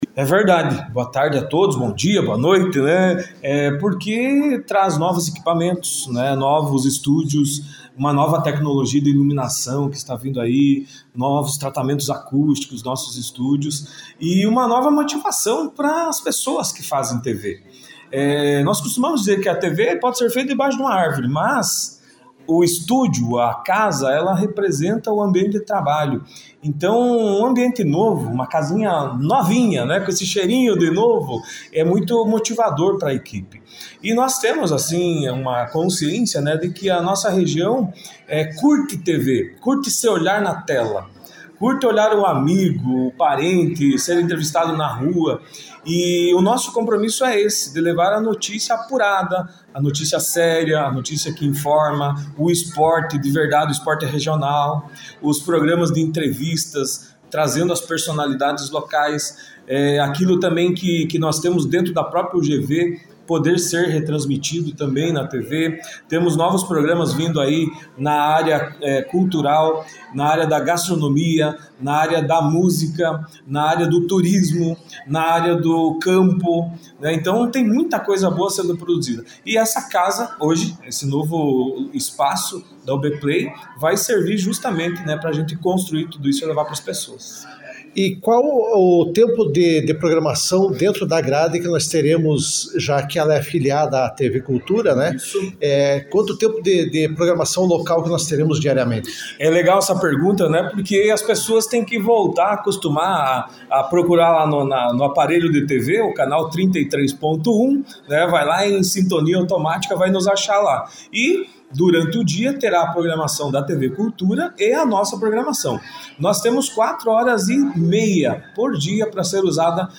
Na noite desta quinta-feira (14), a TV UBPLAY inaugurou oficialmente seu novo e moderno prédio em União da Vitória.